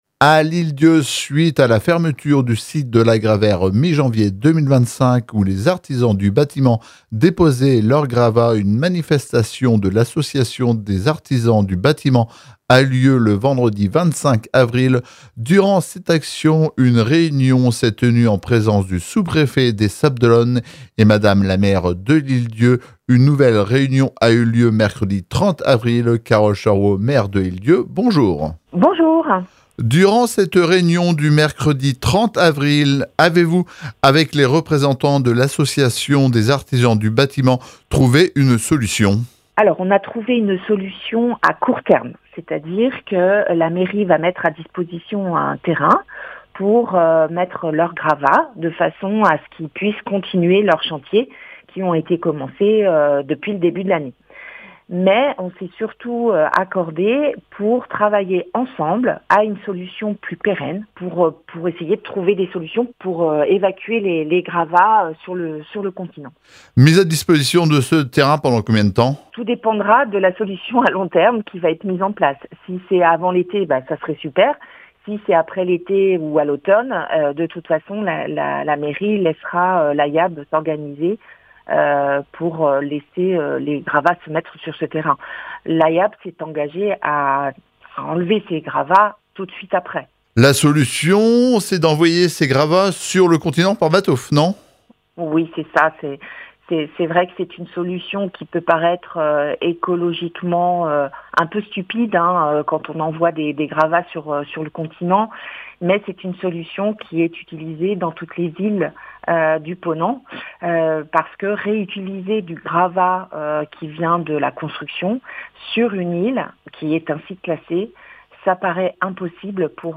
Carole Charuau Mairie de L’Ile d’Yeu en interview.